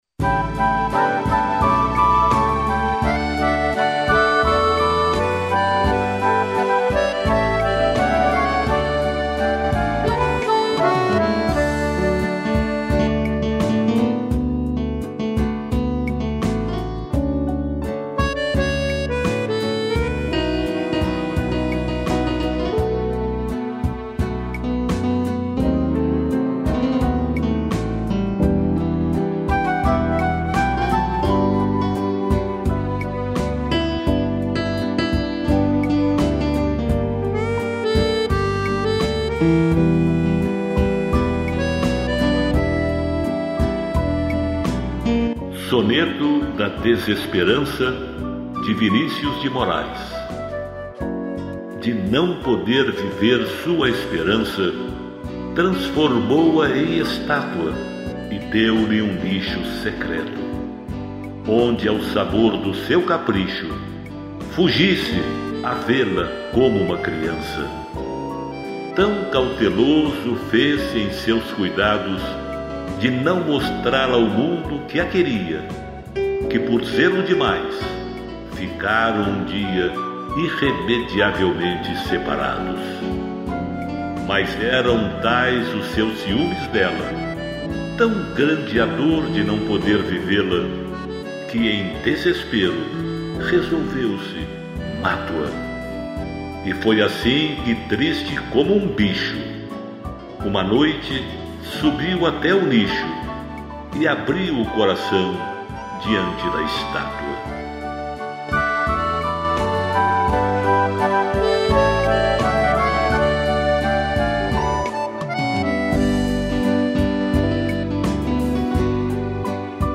piano, flauta, cello e acordeão